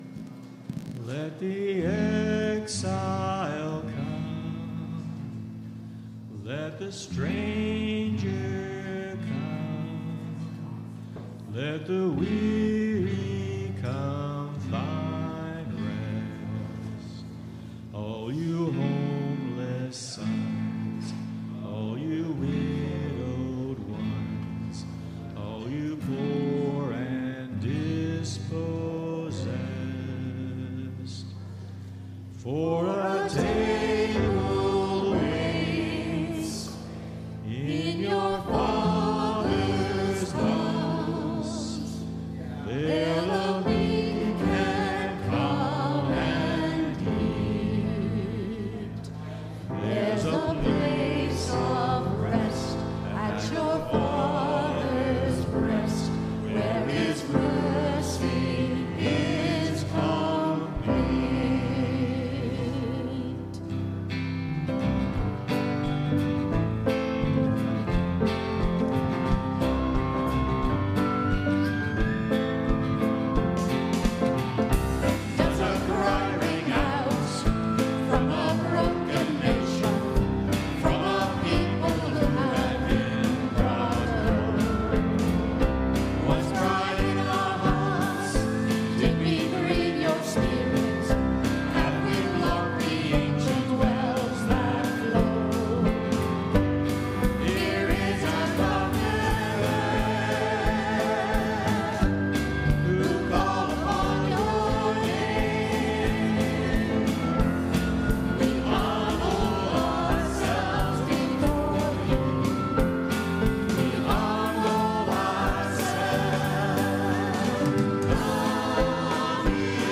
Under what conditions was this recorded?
WORSHIP - 10:30 a.m. Second Sunday in Lent